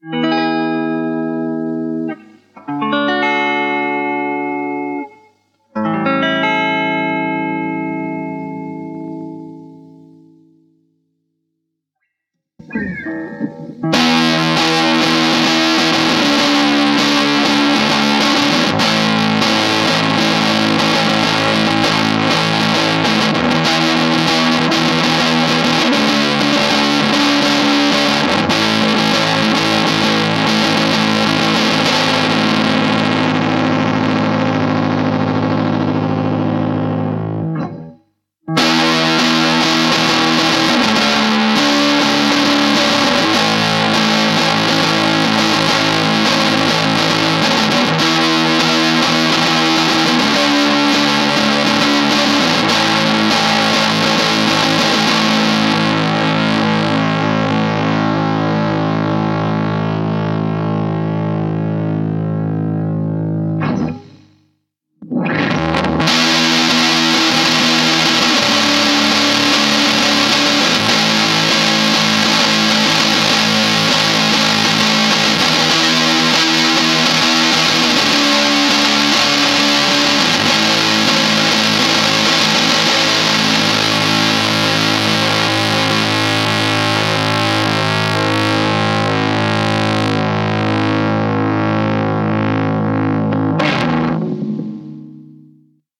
Rhythm Neck Sounds [No pedal, Benderness 0%, 50%, 100%]
Il Mr. Bender è un fuzz ispirato al famosissimo Tone Bender ma è dotato un tono tutto suo, carico di armoniche e cattivissimo.
Maggiore è la benderness maggiore è la quantità di armonici cremosi e taglienti che il Mr. Bender vi regalerà.
mr_blender_lp_neck_rithm_VHT.mp3